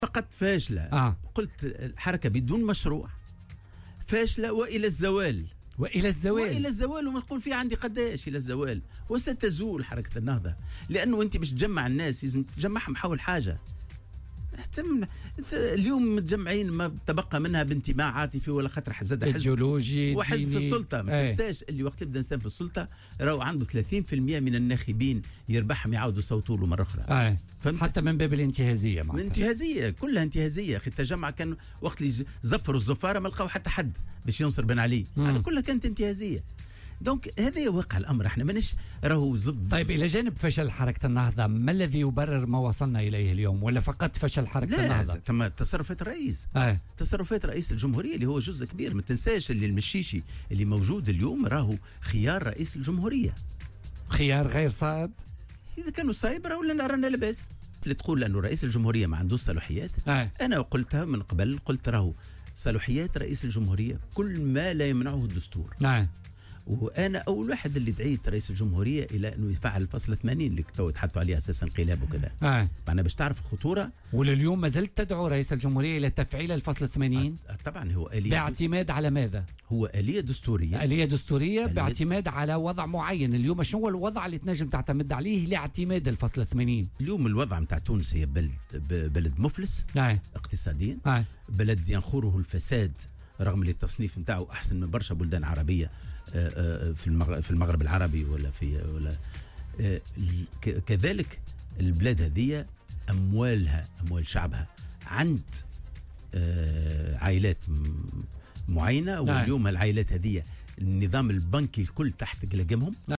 وأضاف في مداخلة له اليوم في برنامج "بوليتيكا" أن اختيار هشام المشيشي على رأس الحكومة اختيار خاطئ منذ البداية.